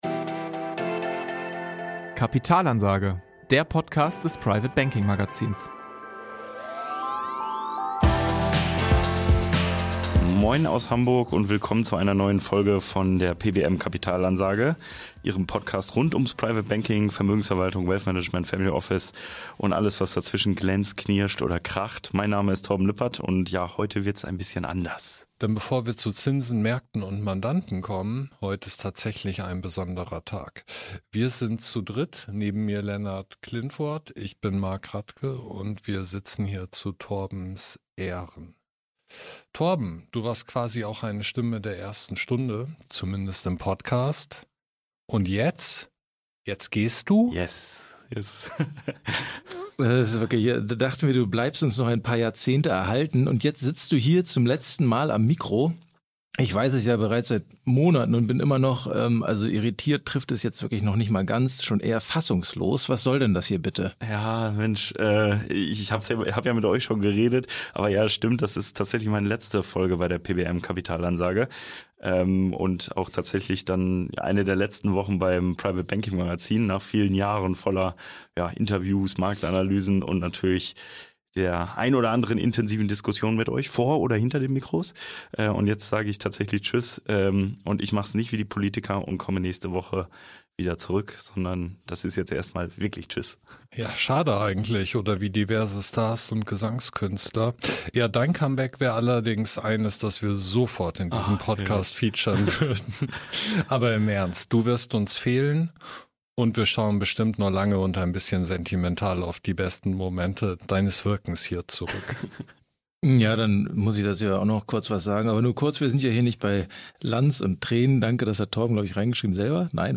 sitzen erstmals drei Moderatoren vor den Mikros der „pbm kapitalansage“. Für wen es das letzte Mal ist und was Single Family Officer gerade fürchten, hören Sie in der neuen Folge.